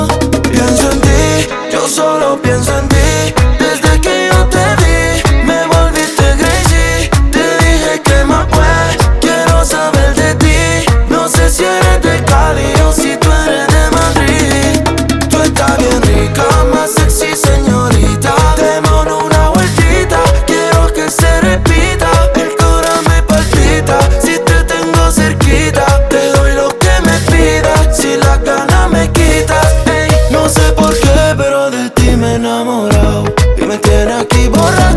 Страстные ритмы латино
Urbano latino
Жанр: Латино